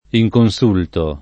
inconsulto [ i j kon S2 lto ] agg.